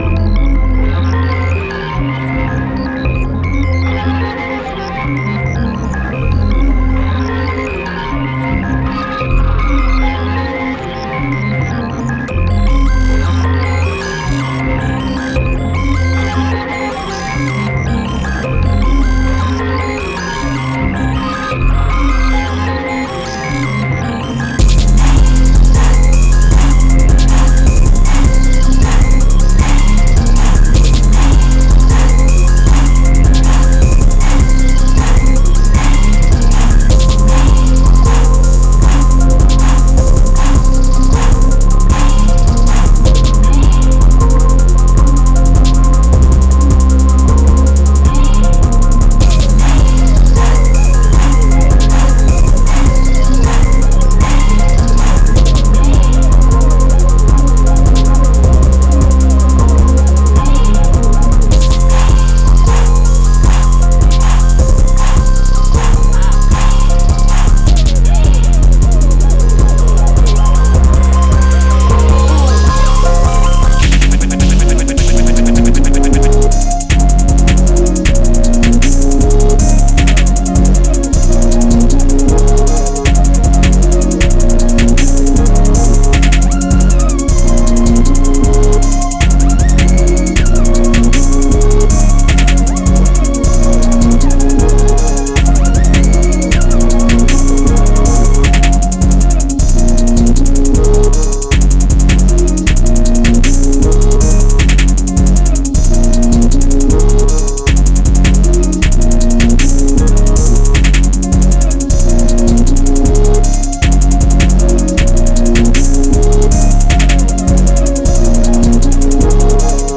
fast, chaotic, and impossible to ignore.